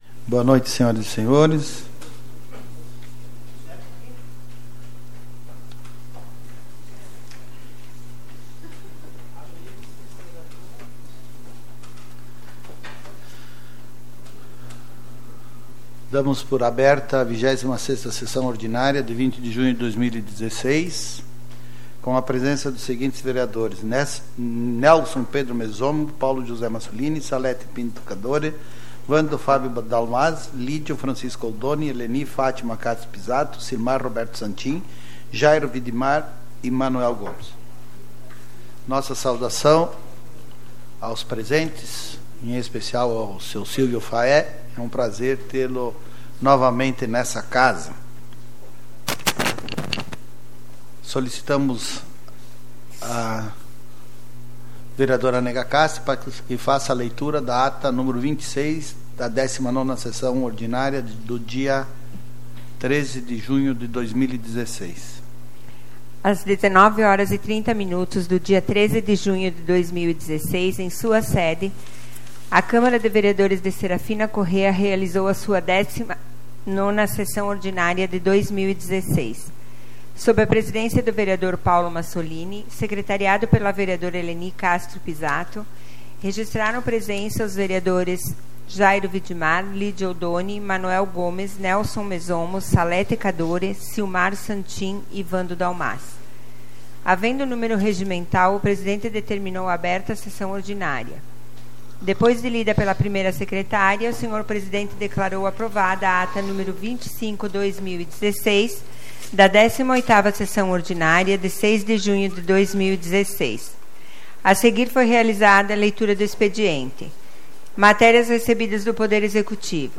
SAPL - Câmara de Vereadores de Serafina Corrêa - RS
Tipo de Sessão: Ordinária